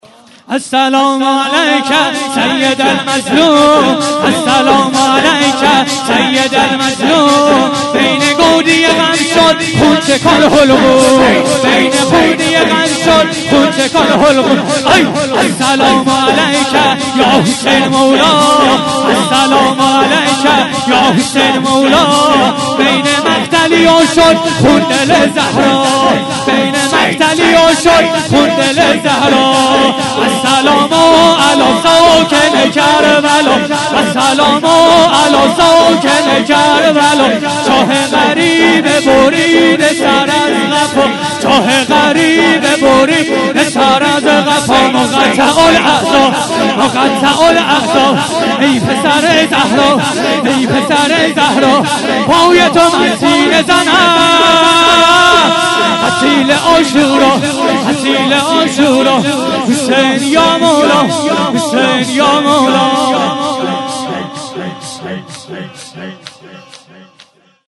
9- السلام علیک سید المظلوم - شور